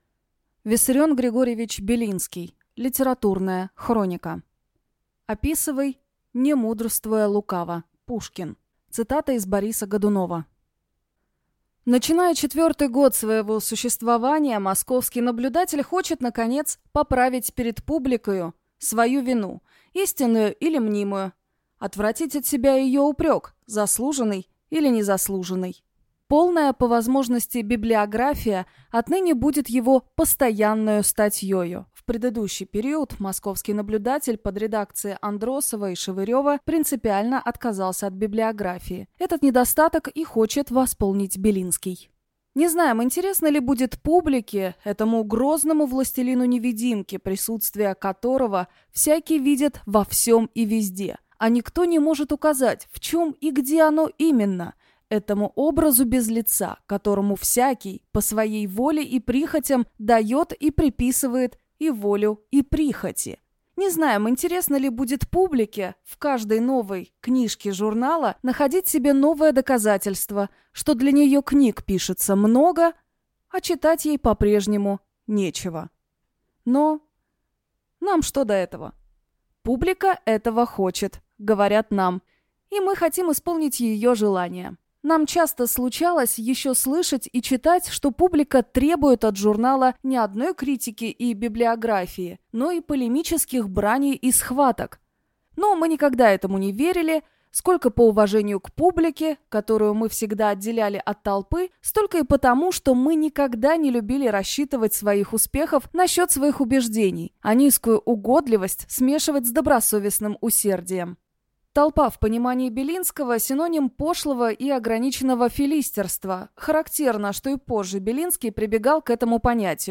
Аудиокнига Литературные мечтания | Библиотека аудиокниг